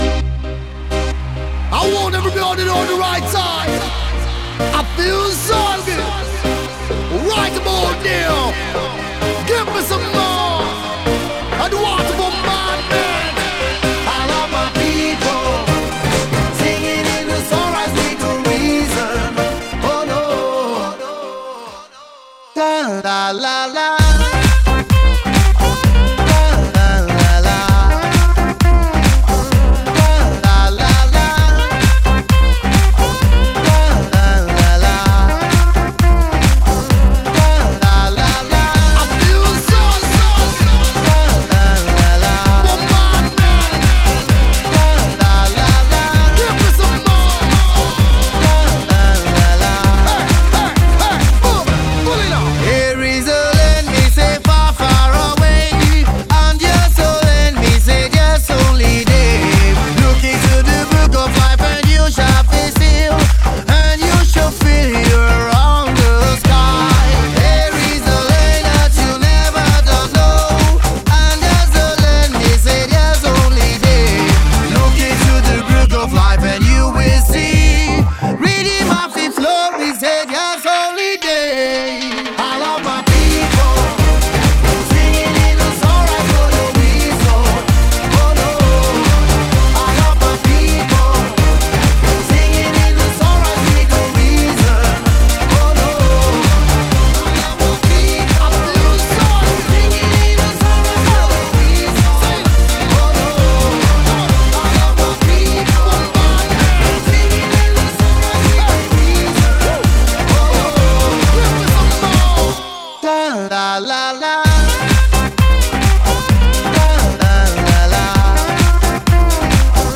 BPM130